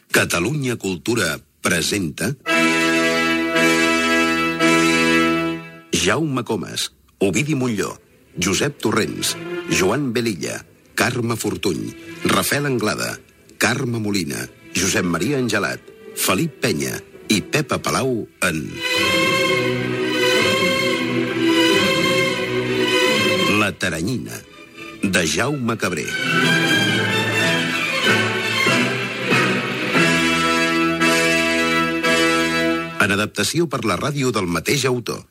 Careta del programa
Ficció